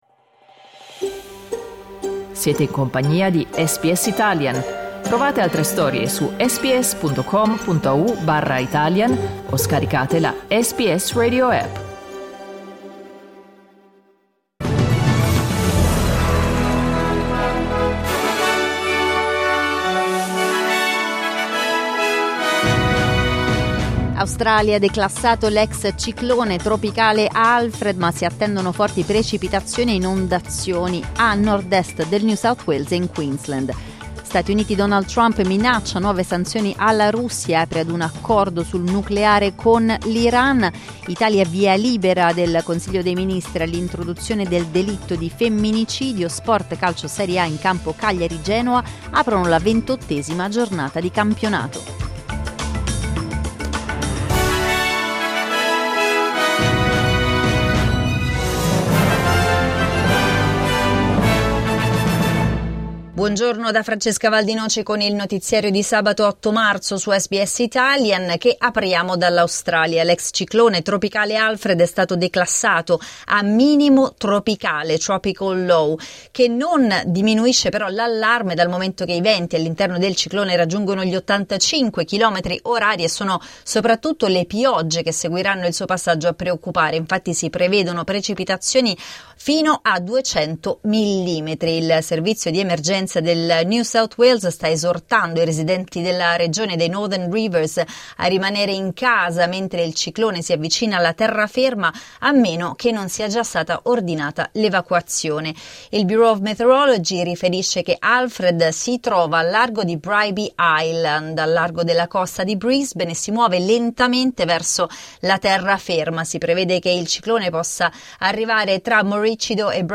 Il notiziario di SBS in italiano.